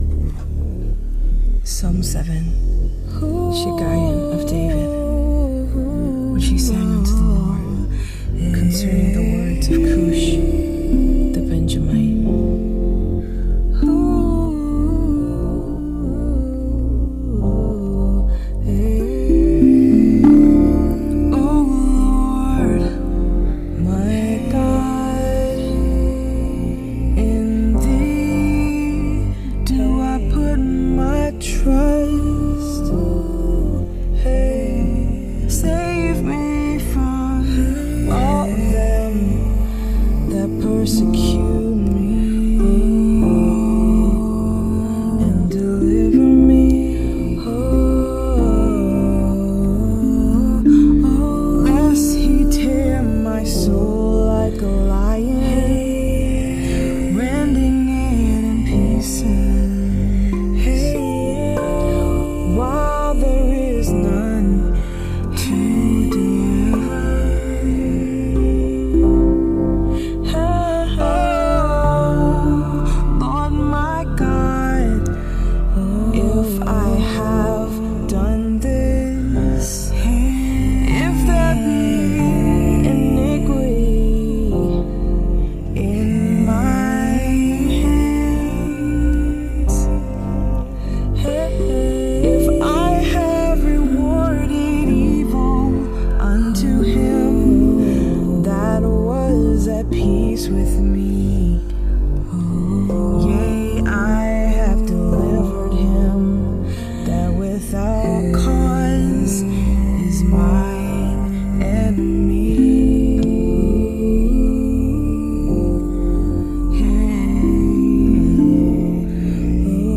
Psalms 7 Rav Vast & Beats Sessions 3-6-25